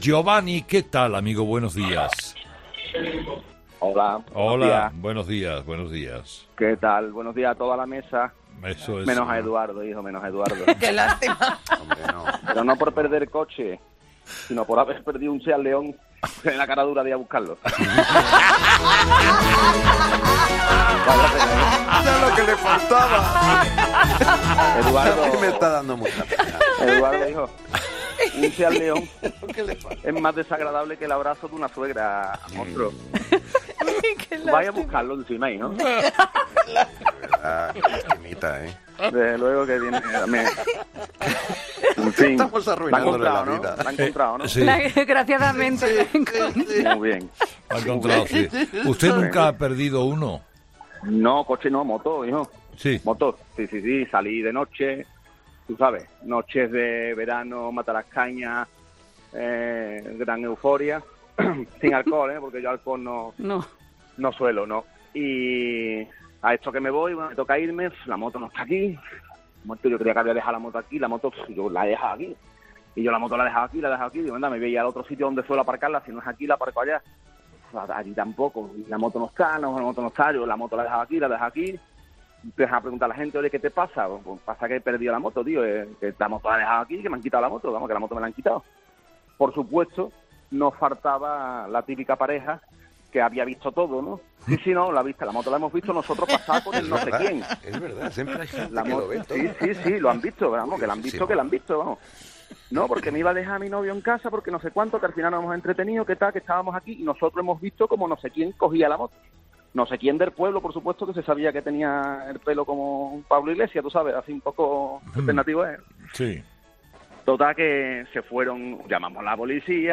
¿Has perdido alguna vez el coche? ¿Confundiste el tuyo con el de otra persona? ¿Ibas con una copa de más? Este lunes los 'fósforos' de 'Herrera en COPE' han relatado sus despistes más sonados.